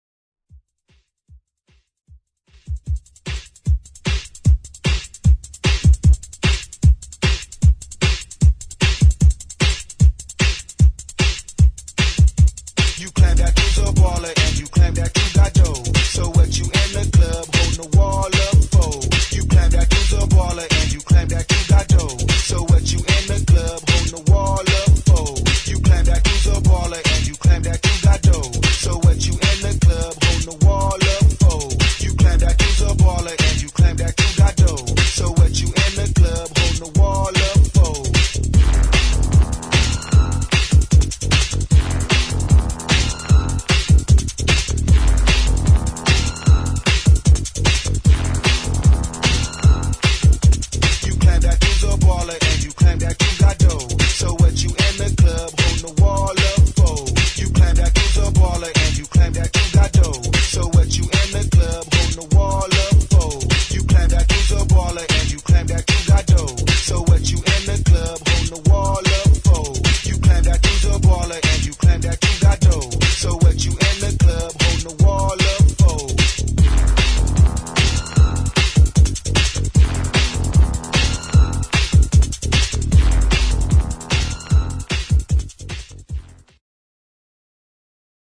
[ GHETTO | ELECTRO ]
エレクトロでファンキーなゲットー・テック・ベース！